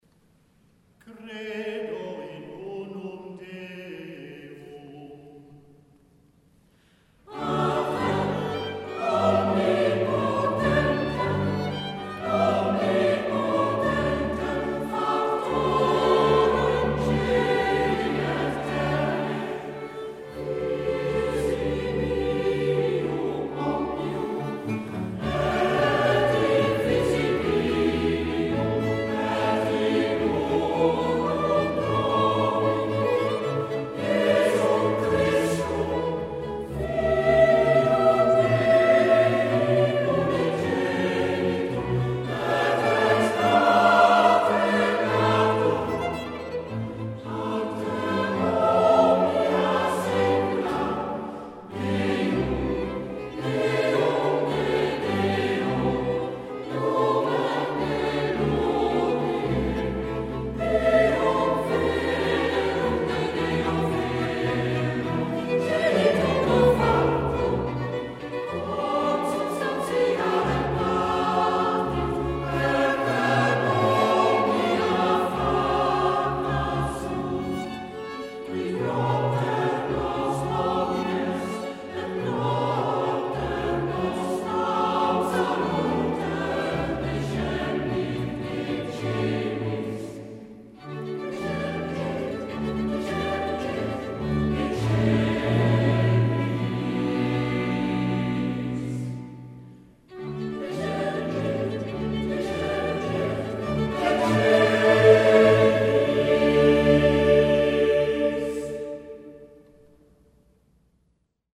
Op deze pagina zijn diverse live opnamen te horen,
...geen studio kwaliteit...
live opname van andere concerten: